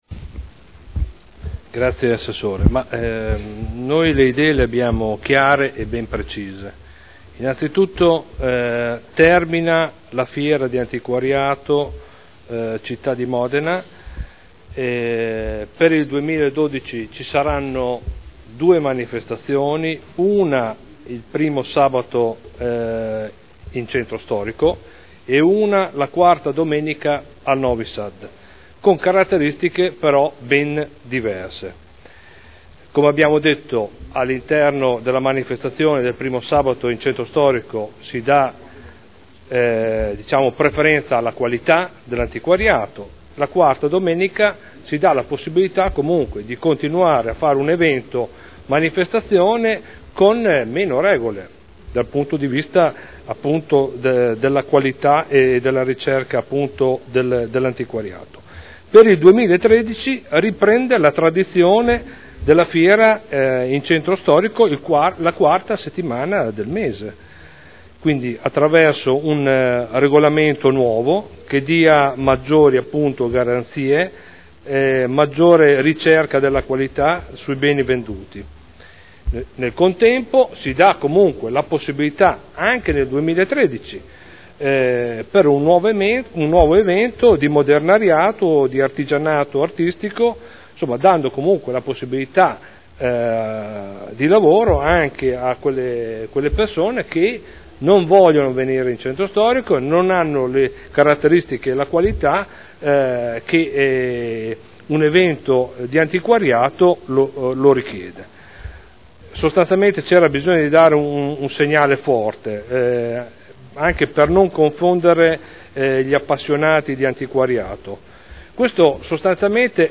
Stefano Prampolini — Sito Audio Consiglio Comunale
Dibattito su nuovo ordine del giorno e proposta di deliberazione.